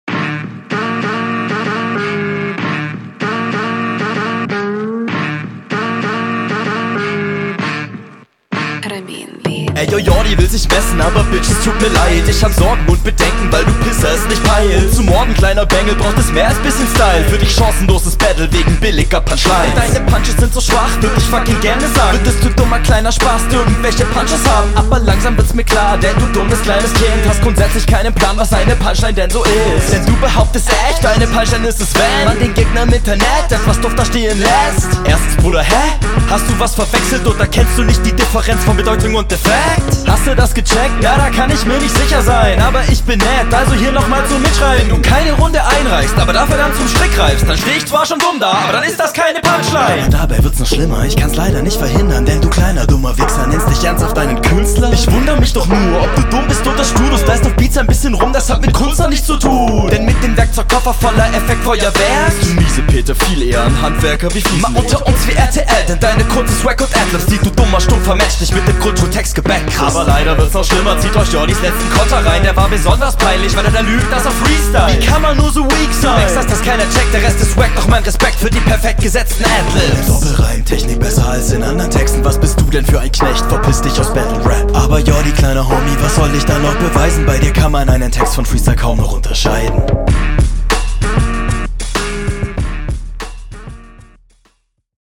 Beat steht dir eindeutig besser und du passt da stimmlich gut drauf.